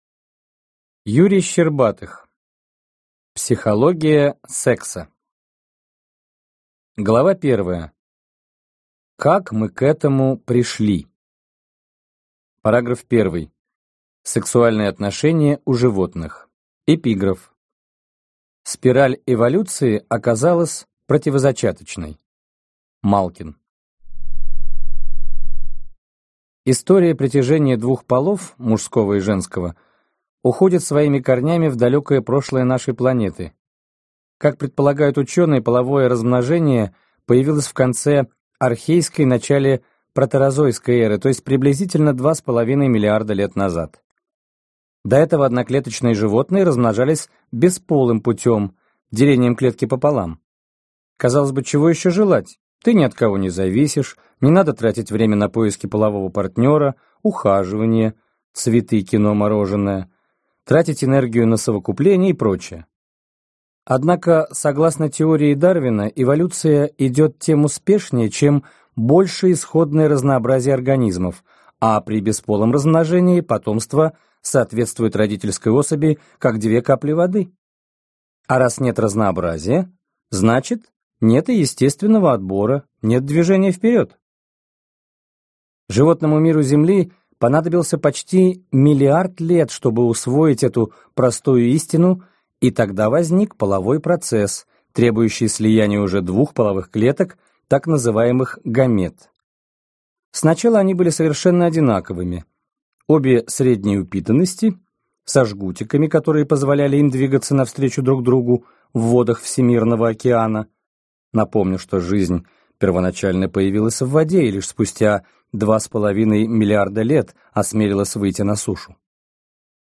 Аудиокнига Психология секса. Мужской взгляд | Библиотека аудиокниг